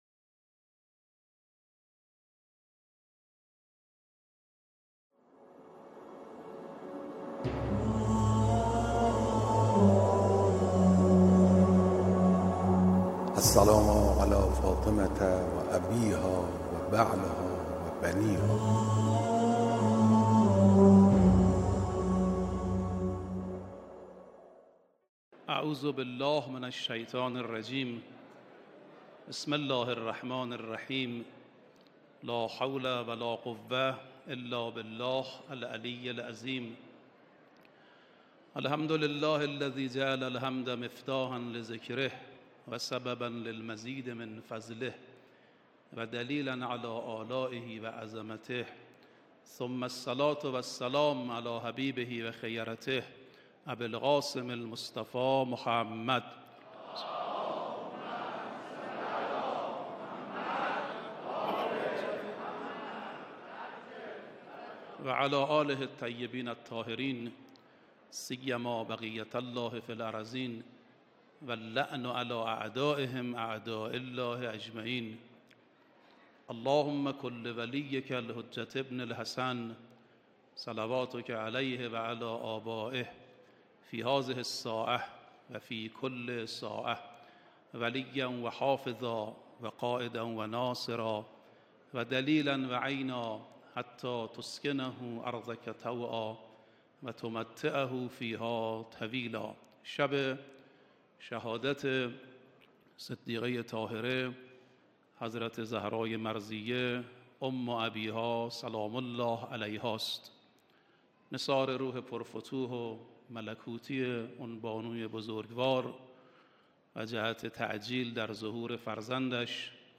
مراسم عزاداری شب شهادت حضرت ‌زهرا سلام‌الله‌علیها
سخنرانی